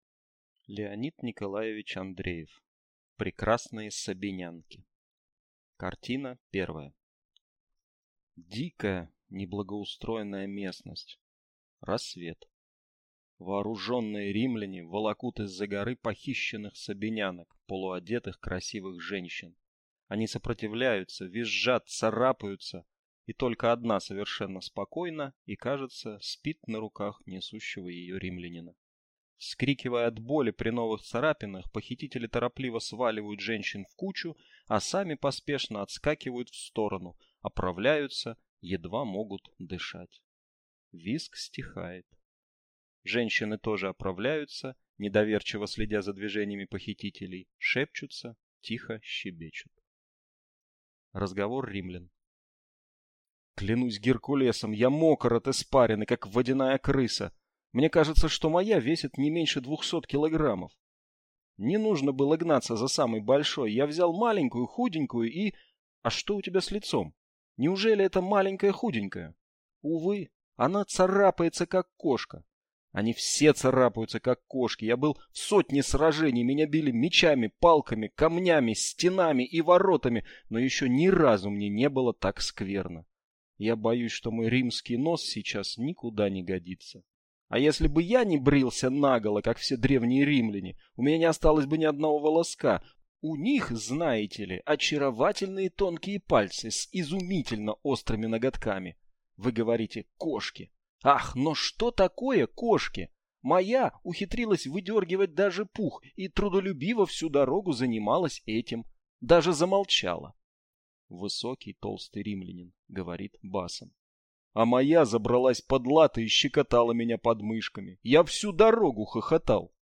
Аудиокнига Прекрасные сабинянки | Библиотека аудиокниг